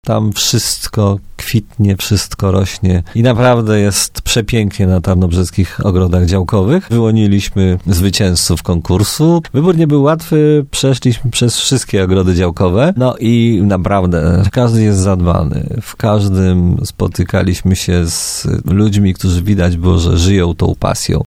– Konkurs ma na celu przede wszystkim zachęcić zarządy Rodzinnych Ogrodów Działkowych do dbałości o ROD-y, zachęcić mieszkańców, działkowców do dbałości o swoje ogródki działkowe – powiedział prezydent Tarnobrzega, Dariusz Bożek, który stał na czele komisji oceniającej.